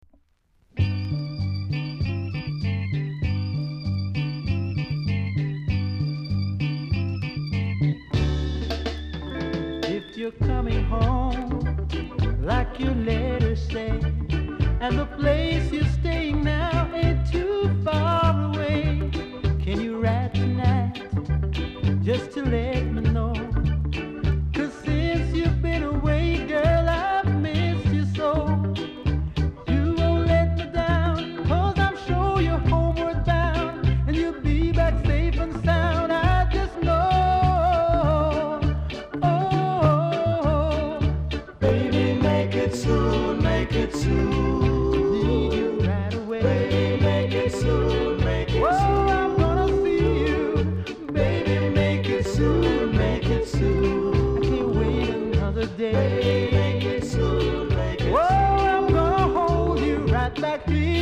※小さなチリノイズが少しあります。
素晴らしいSOULFUL REGGAE VOCAL!!